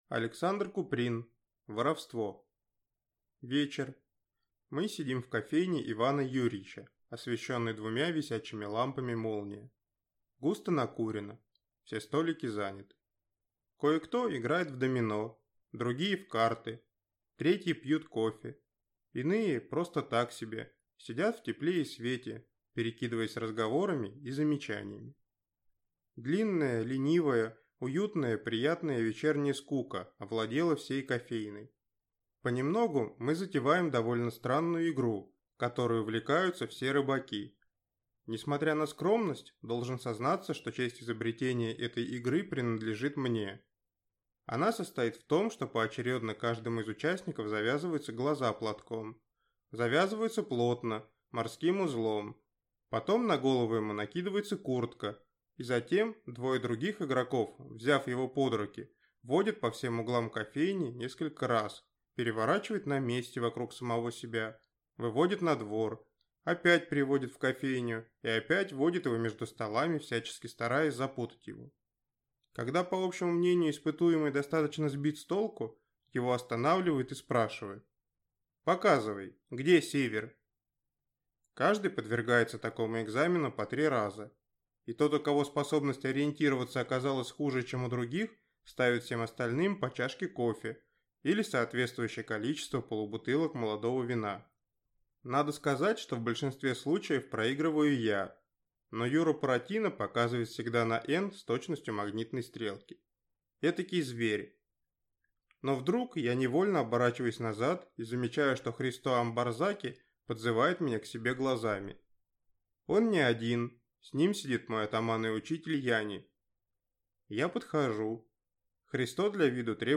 Аудиокнига Воровство | Библиотека аудиокниг